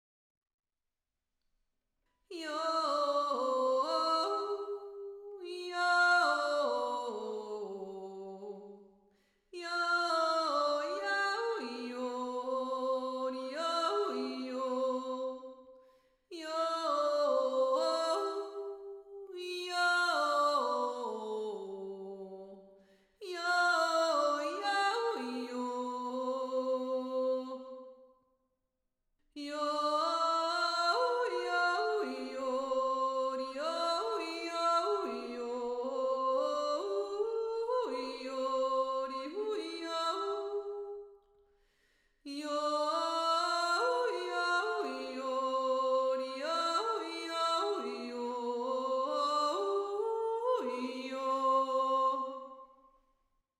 1. Stimme